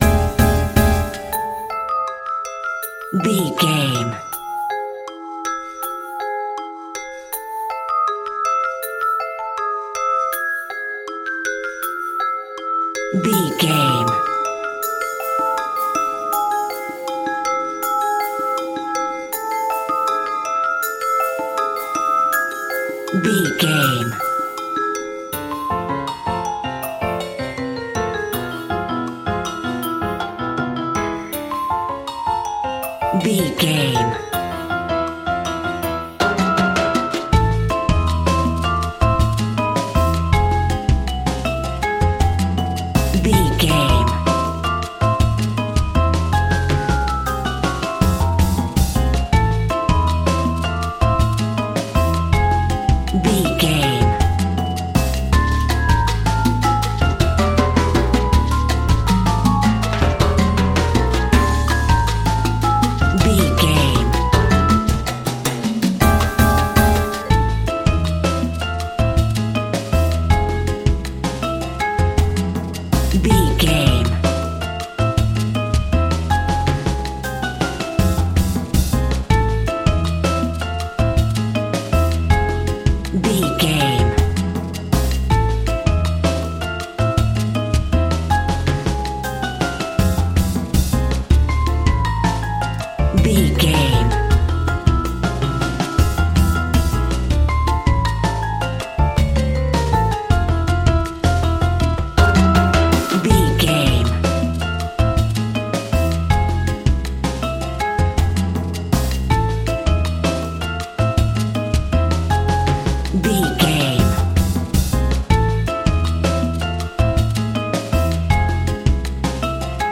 Aeolian/Minor
percussion
silly
circus
goofy
comical
cheerful
perky
Light hearted
quirky